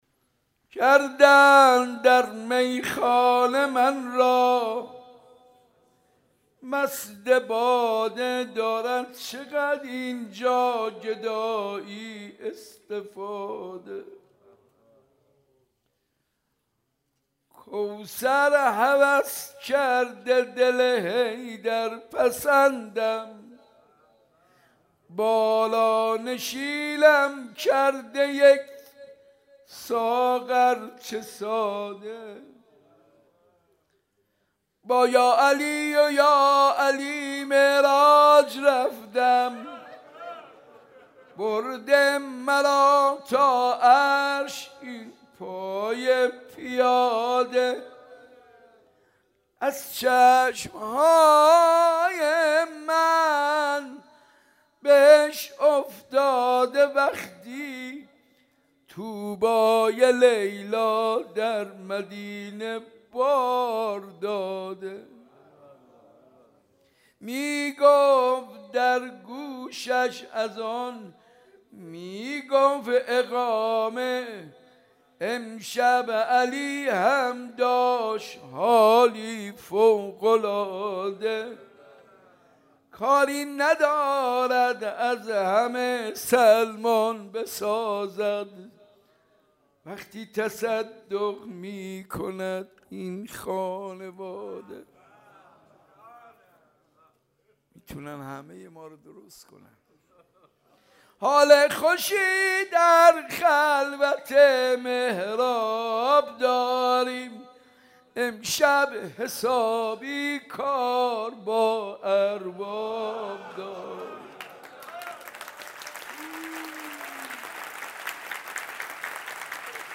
حاج منصور ارضی/حسینیه صنف لباسفروشان/مناجات و روضه حضرت علی اکبر(ع)